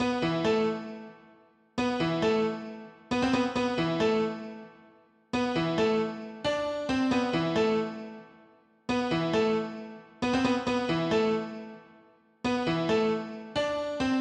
描述：C minor Pentatonic scale
标签： 135 bpm Trap Loops Piano Loops 2.39 MB wav Key : C
声道立体声